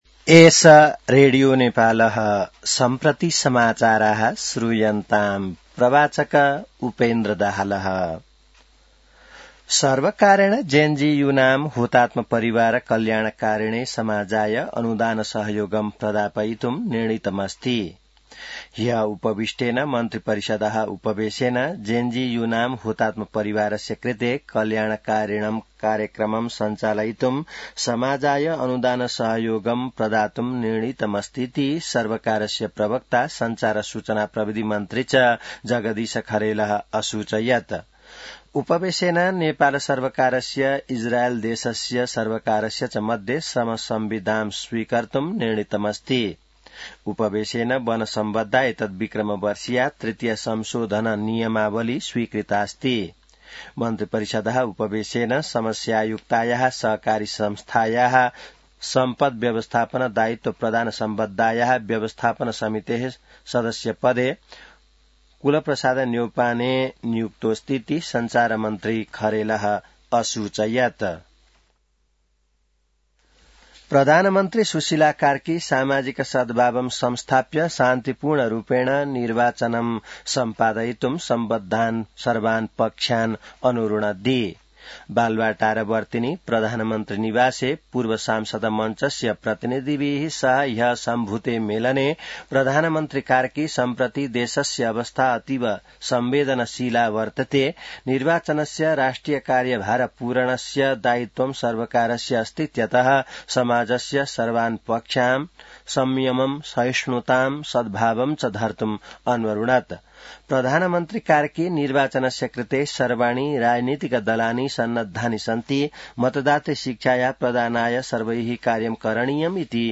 संस्कृत समाचार : २२ पुष , २०८२